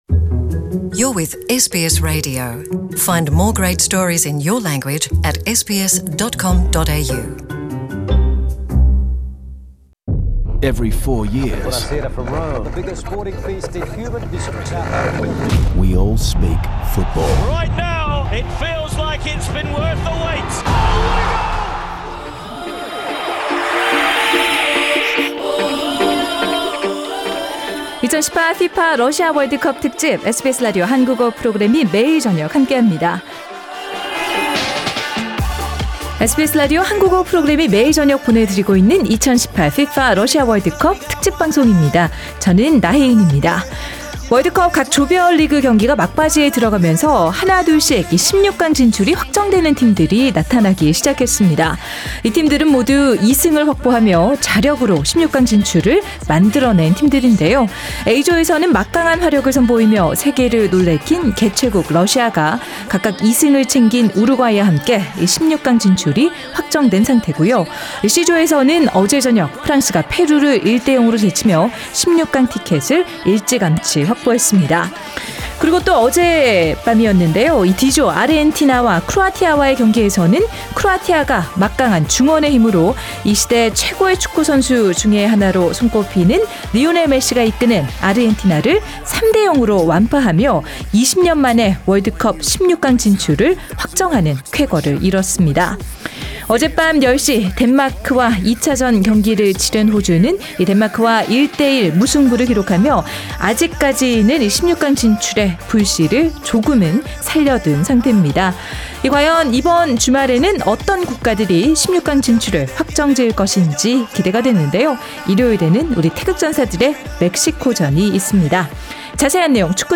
The panel also shares their analysis and prediction about the match Korea against Mexico. The full World Cup Panel discussion is available on the podcast above.